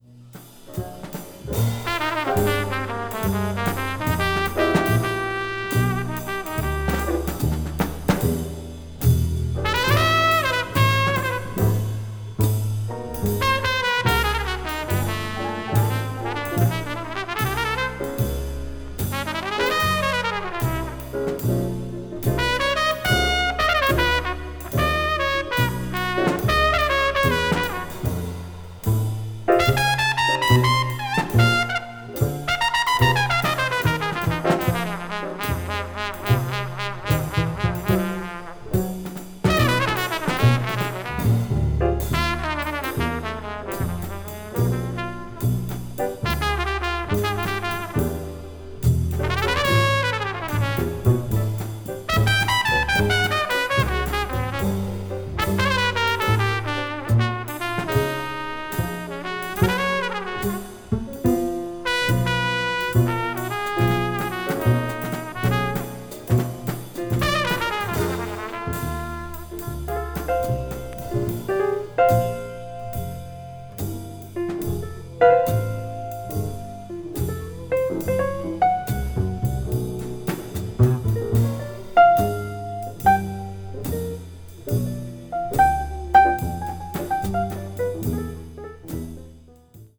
blues jazz   hard bop   modern jazz